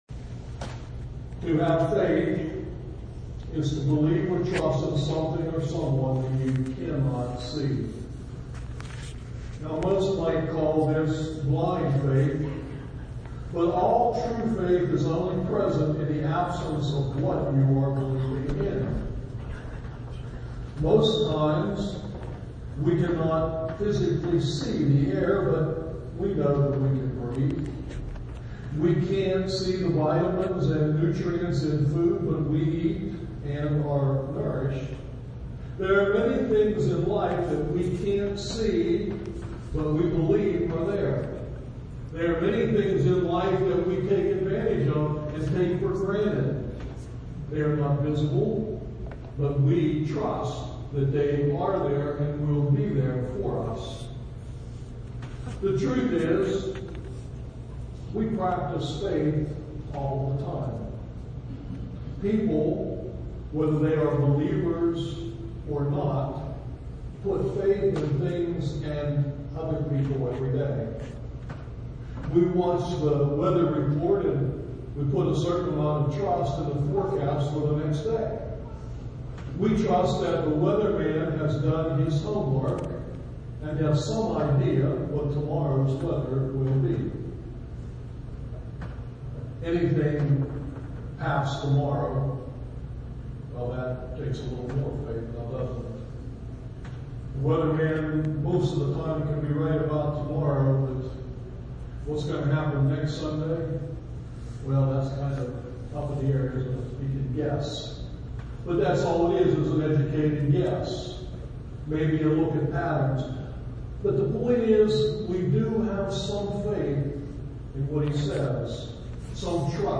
SERMON TEXT: Matthew 14:22-33